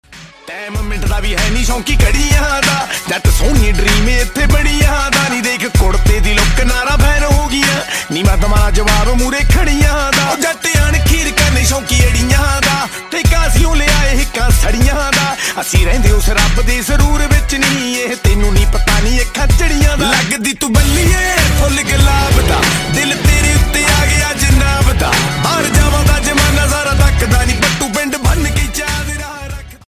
powerful and energetic track